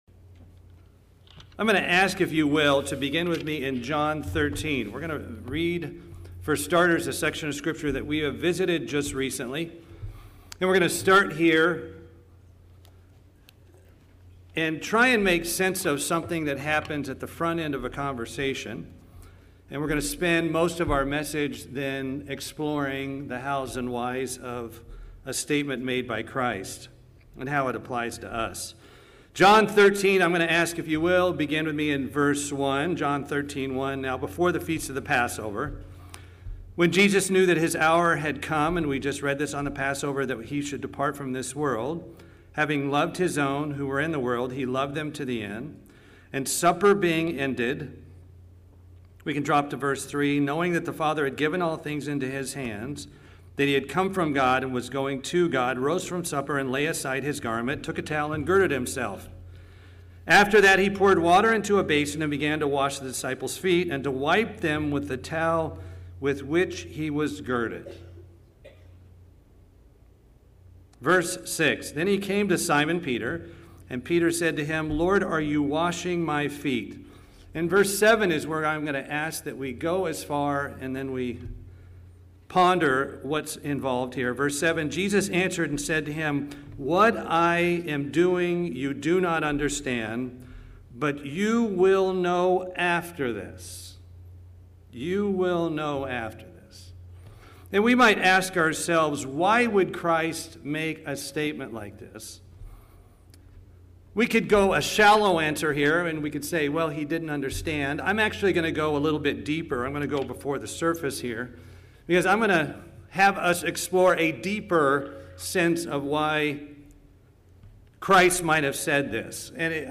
In this sermon we're challenged to evaluate how we're doing in our Christian walk with God.